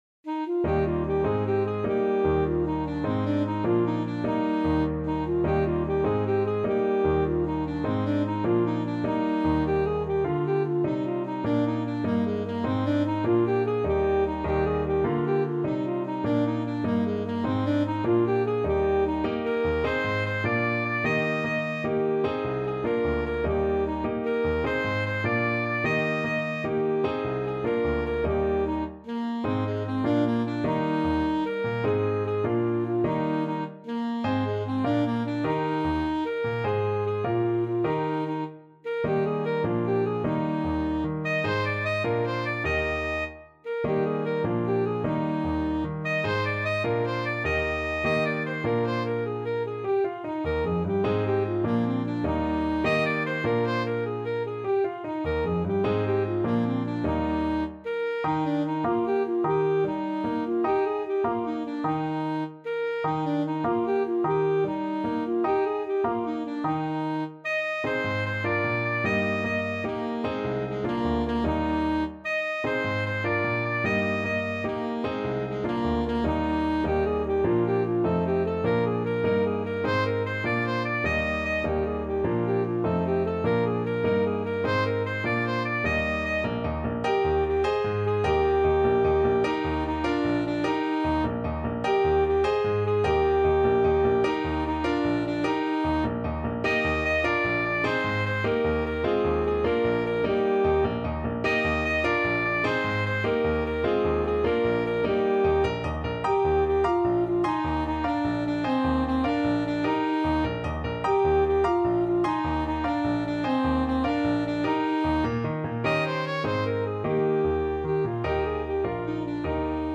Alto Saxophone
6/8 (View more 6/8 Music)
Classical (View more Classical Saxophone Music)